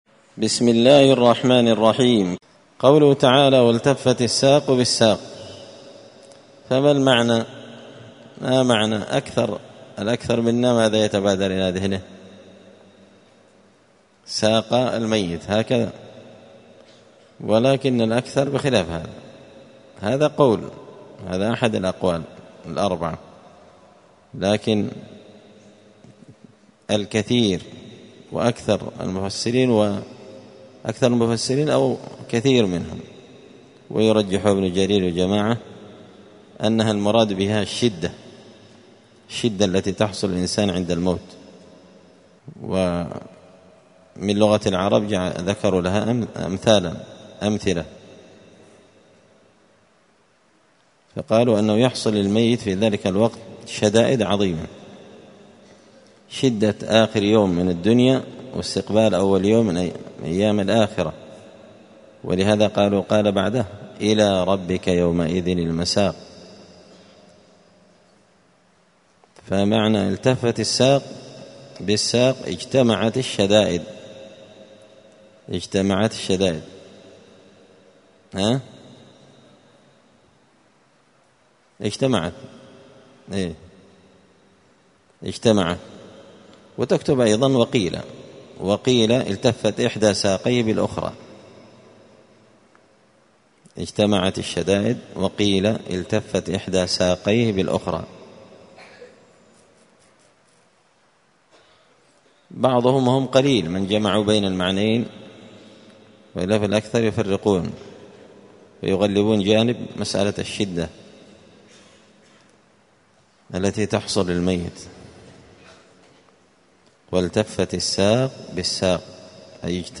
مسجد الفرقان قشن_المهرة_اليمن